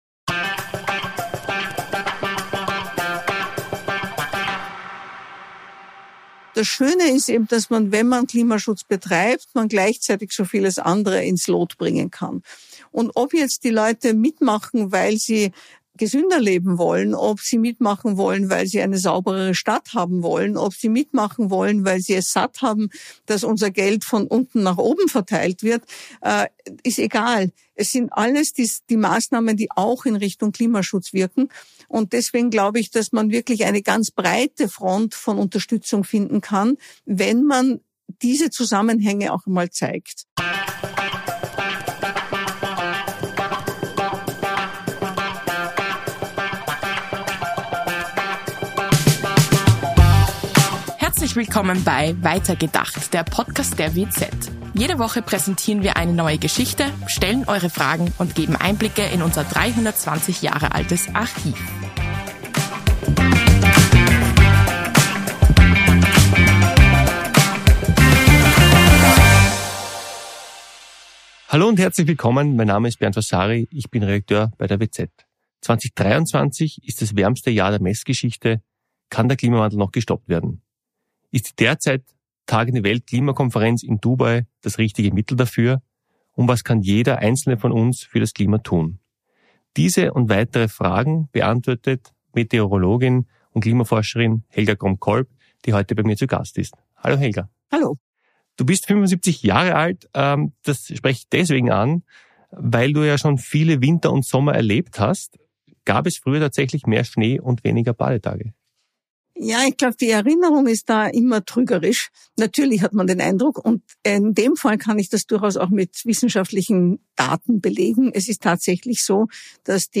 Beschreibung vor 2 Jahren Was kann jeder Einzelne für das Klima tun, warum sollen nicht sofort alle Atomkraftwerke abgeschaltet werden und was ist nötig, für ein weltweites Umdenken? Diese und viele weitere Fragen beantwortet Meteorologin und Klimaforscherin Helga Kromb-Kolb im Gespräch